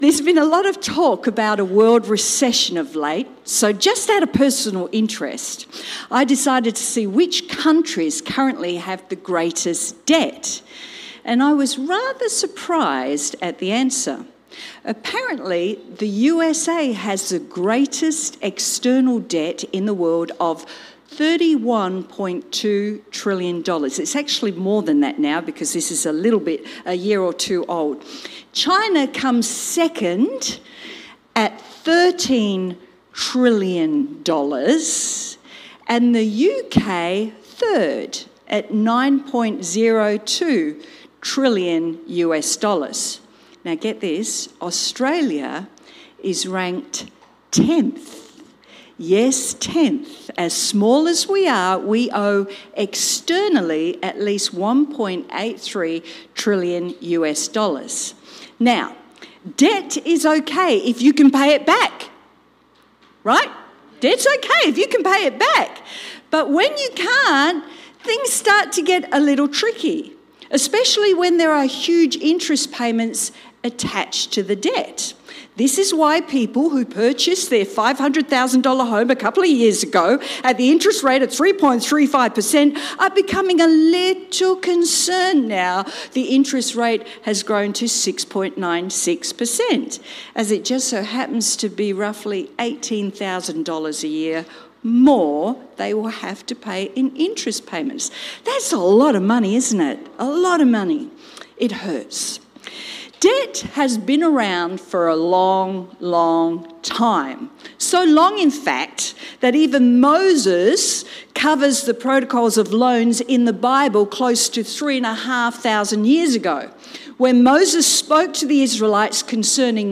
How does God handle debt? Sermon